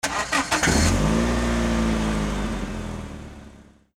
engine.mp3